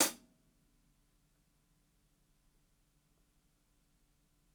ROOMY_HH_3.wav